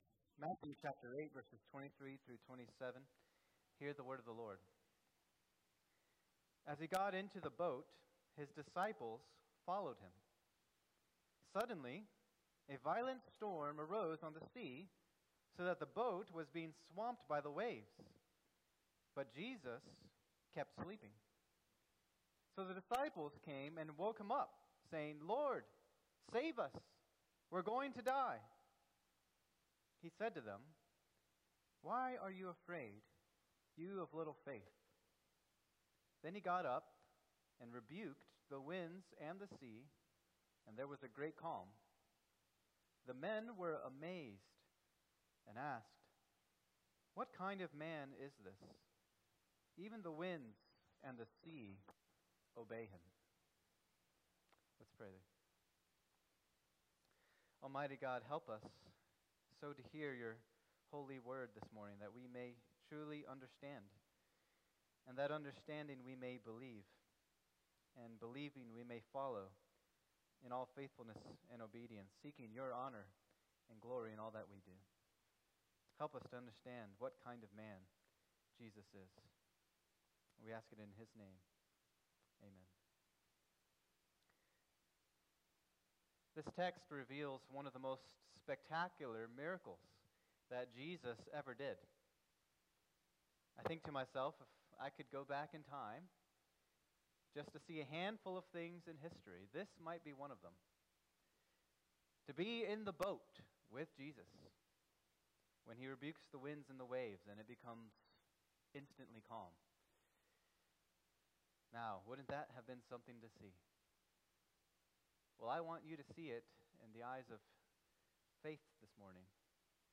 Sermon
2025 at First Baptist Church in Delphi, Indiana.